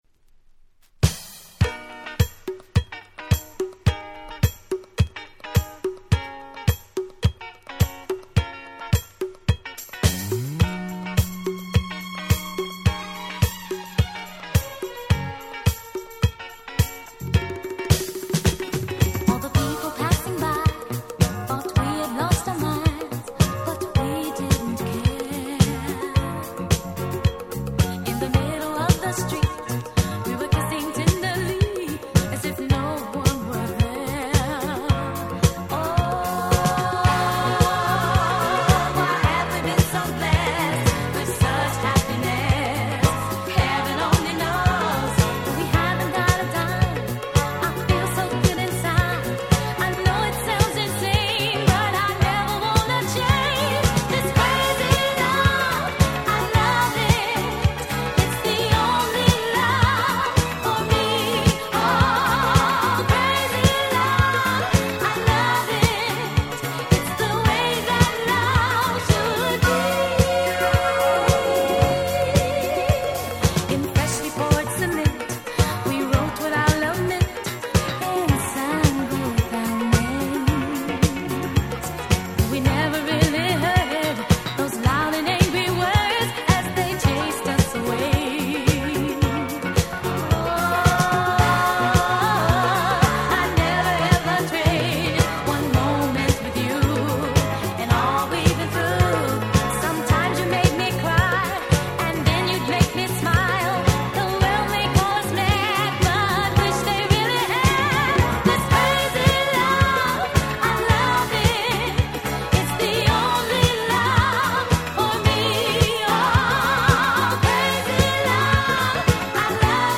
79' Dance Classic超名曲！！